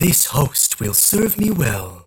50 KB Viscous voice line (unfiltered) - This host will serve me well. 1